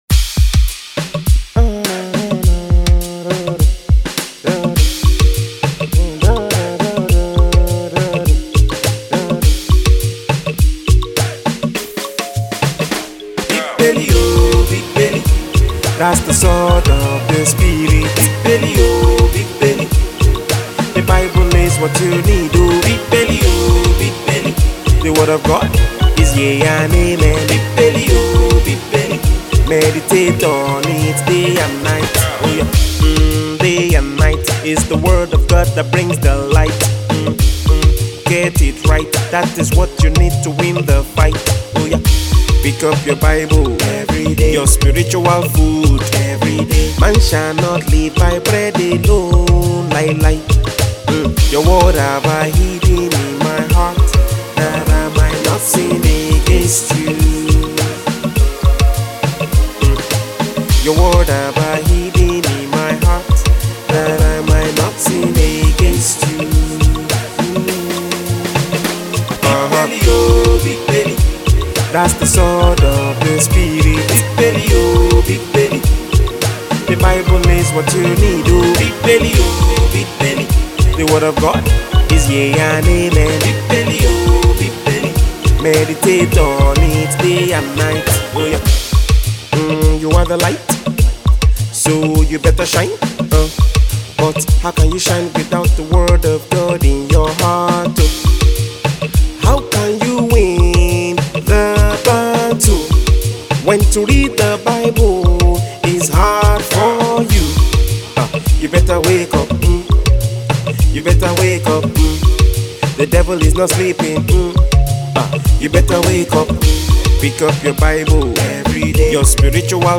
praise & worship
U.S based gospel artist
has a well-crafted instrumentation
sultry vocals